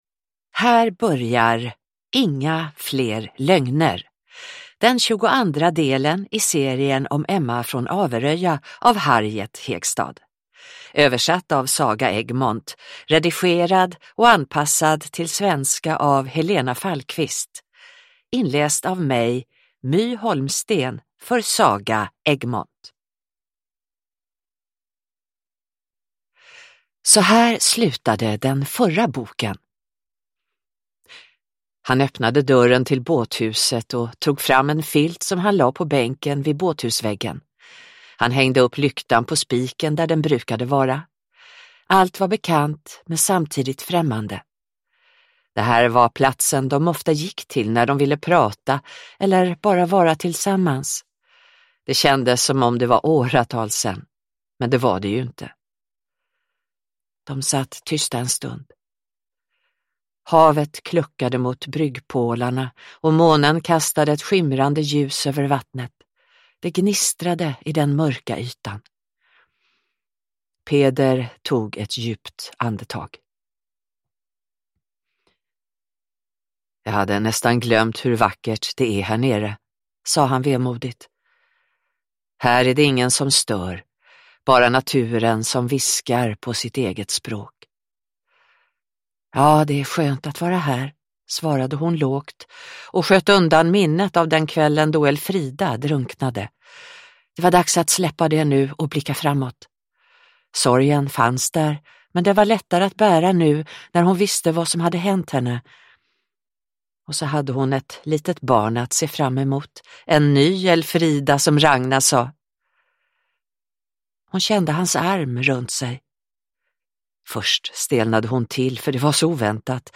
Inga fler lögner – Ljudbok